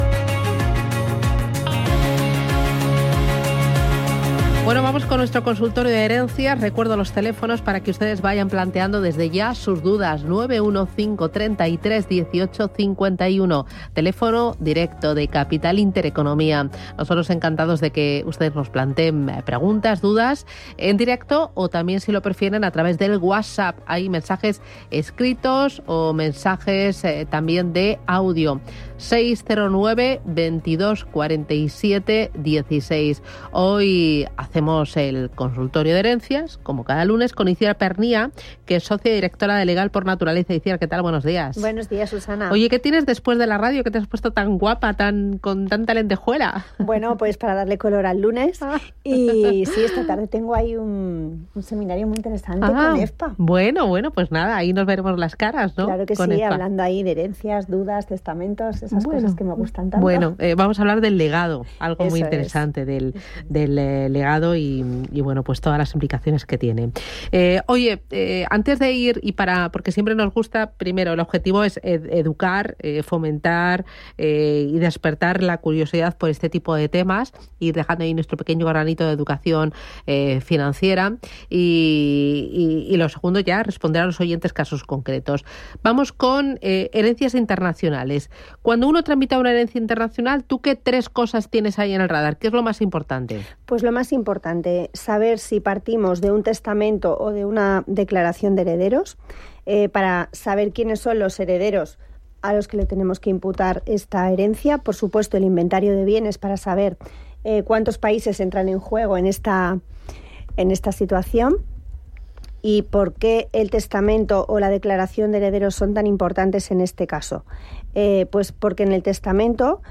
respondió a preguntas de los oyentes
Puede escuchar el consultorio de herencias completo pinchando aquí.